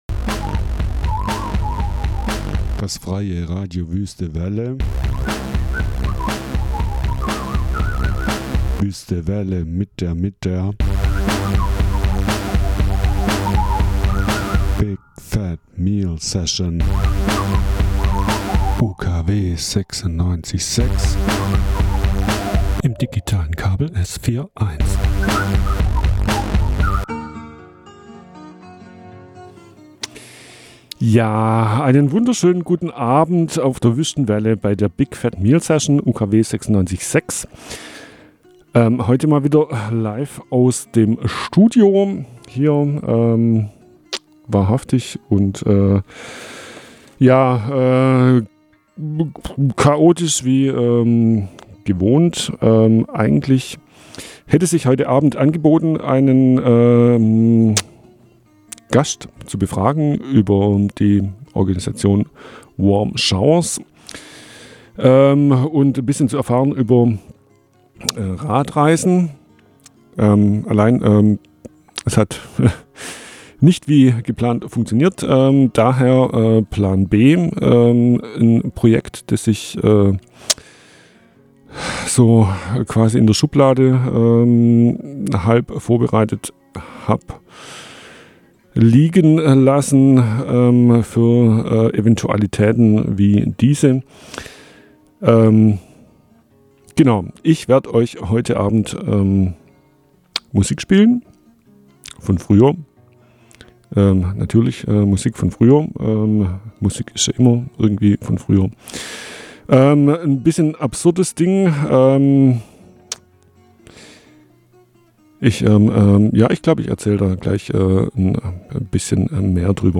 Die BFMS gibt einen ersten Einblick und versucht auch, Verbindungen zwischen den einzelnen Beiträgen aufzuzeigen Man möge die mitunter mangelhafte Soundqualität entschuldigen, Compact-Casetten scheinen sich nicht als Archivierungsmedium für die Ewigkeit zu eignen.